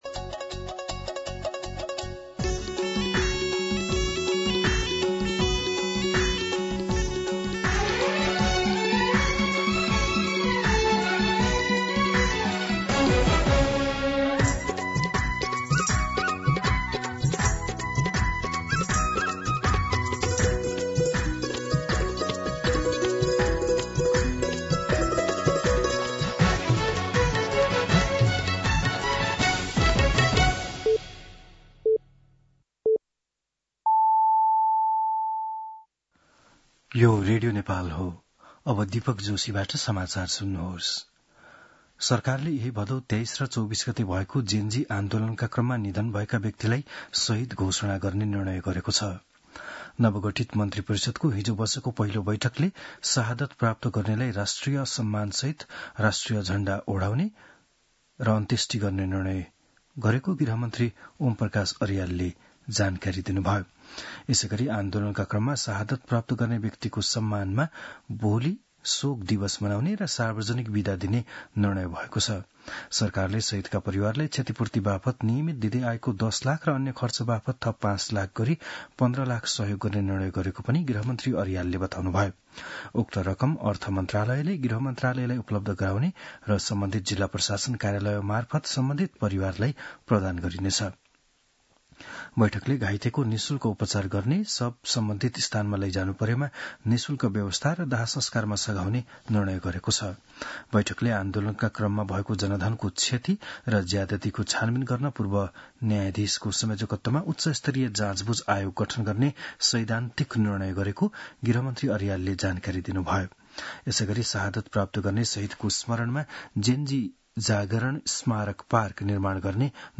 बिहान ११ बजेको नेपाली समाचार : ३१ भदौ , २०८२